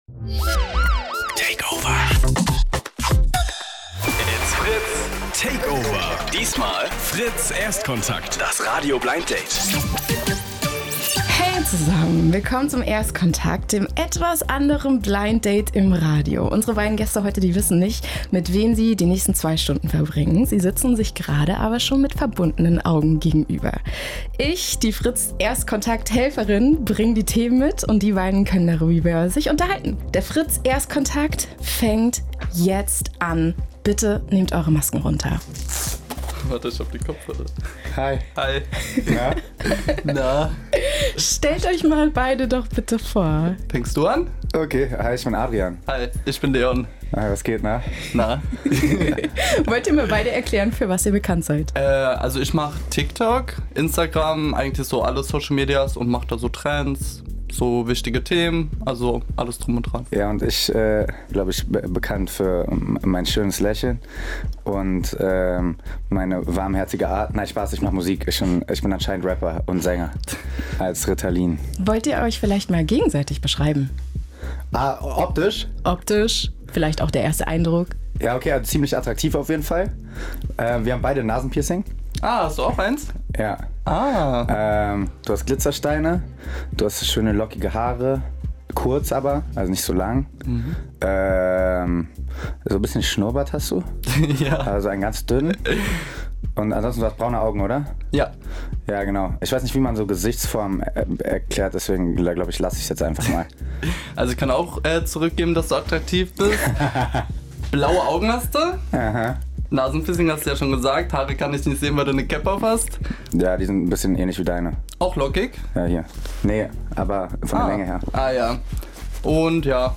Eine Radiosendung, zwei Gäste, die sich nicht kennen und jede Menge Fragen: Das ist FritzErstkontakt, das Radio-Blind-Date.